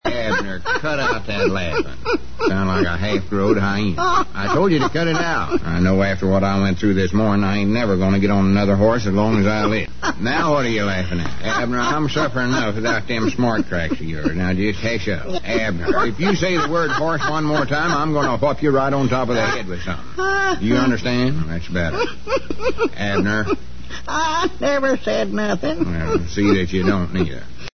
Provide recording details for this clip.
I chopped out everything but Lum's reaction to Abner on this next clip.